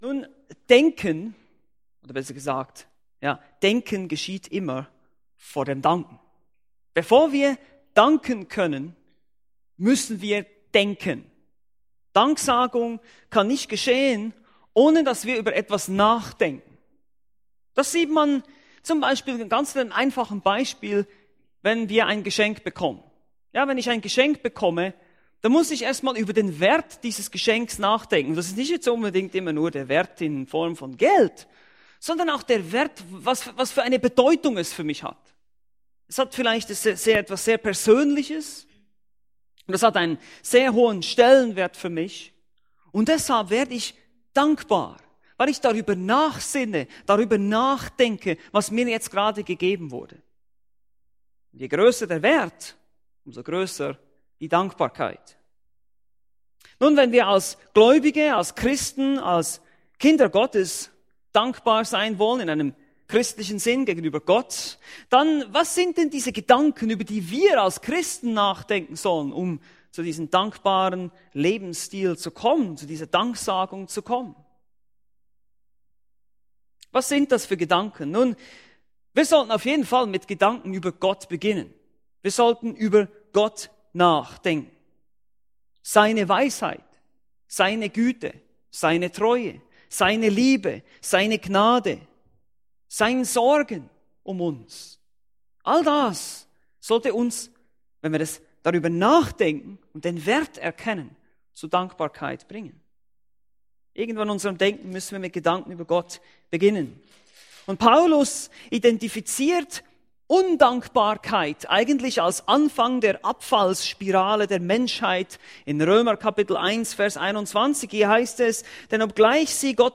Aus der Serie: "Weitere Predigten"